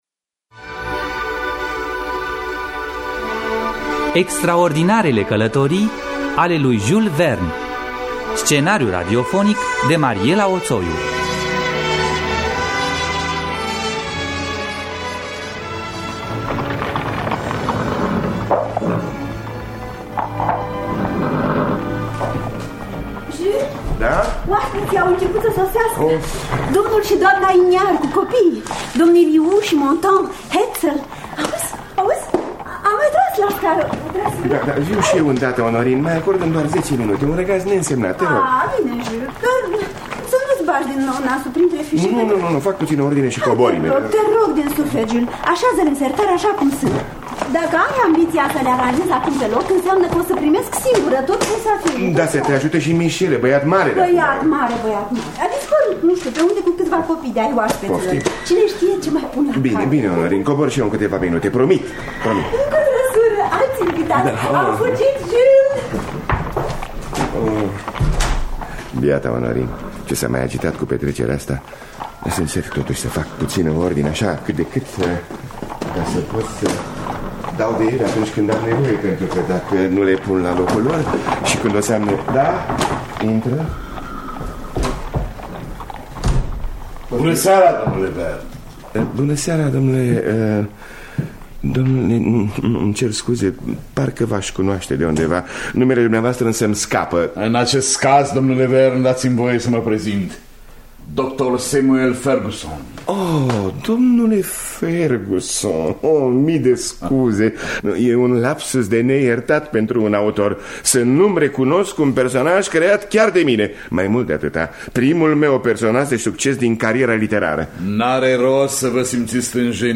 Scenariu radiofonic de Mariela Oțoiu.